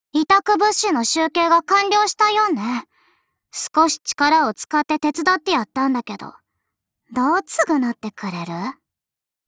贡献 ） 协议：Copyright，人物： 碧蓝航线:菲利克斯·舒尔茨语音 您不可以覆盖此文件。